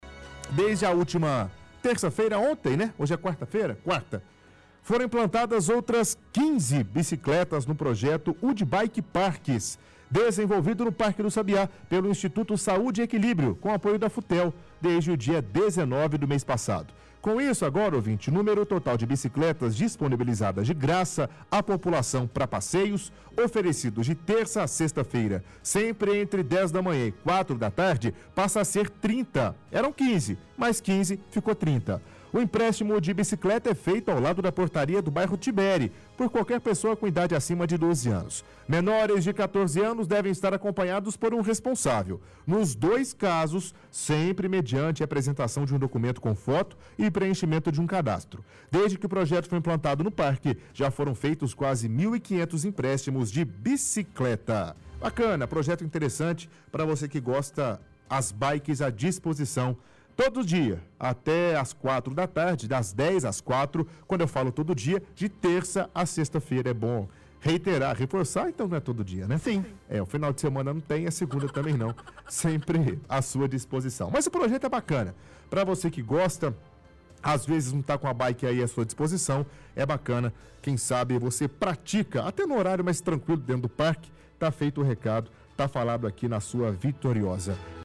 UdiBikes Rádio